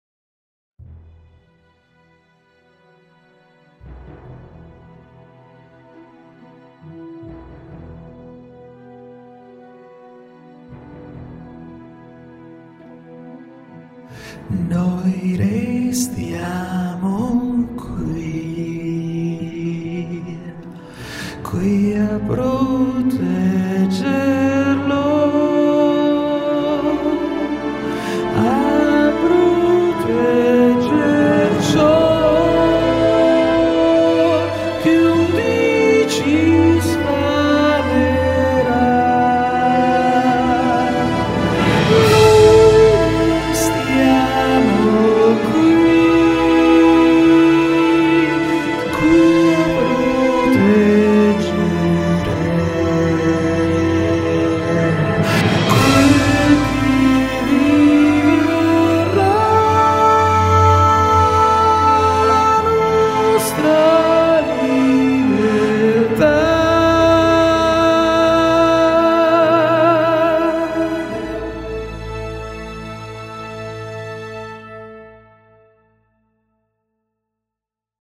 LE VOCI GUIDA FEMMINILE SONO OTTENUTE TRAMITE SINTESI SONORA.
DONNE
contralto-mezzosoprano.mp3